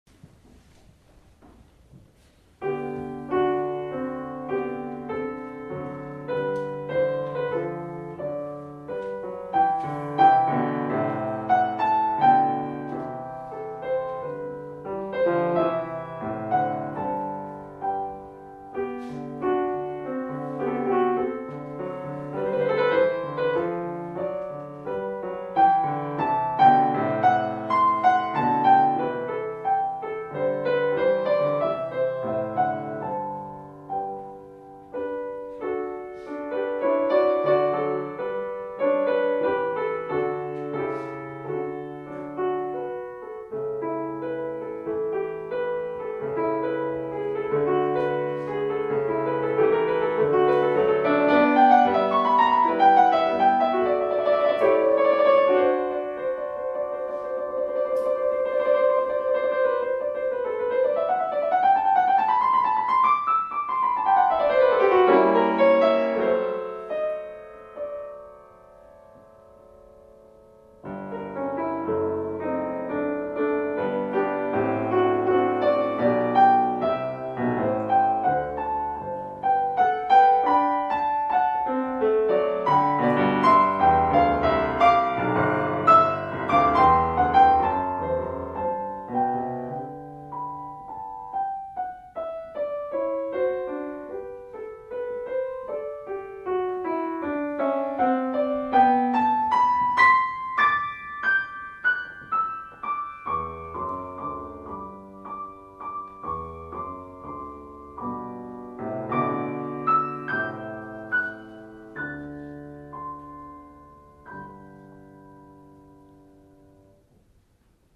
Second concert of the Beethoven-Clementi series at the Royal Academy of Music
piano
Concert Room
1. Andante con moto (cantabile e compiacevole) [mp3]